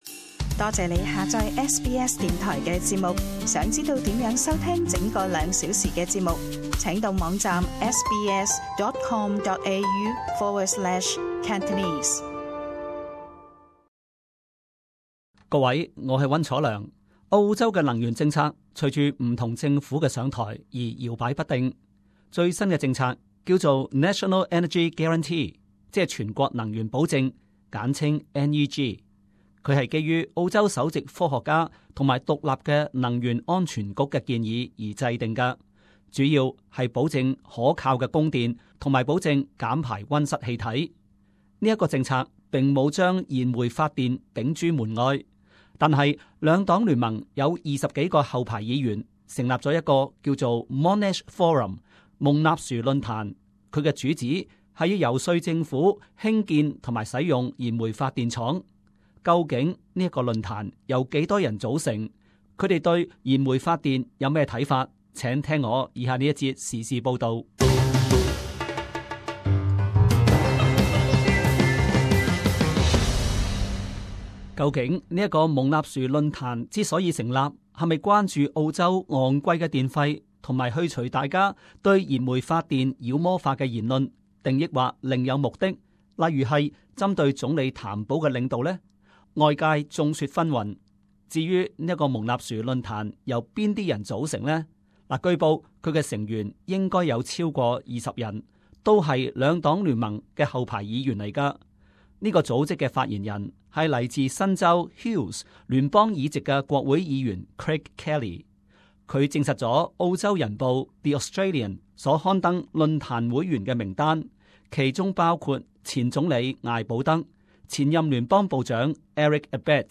【時事報導】 蒙納殊論壇爭取燃煤發電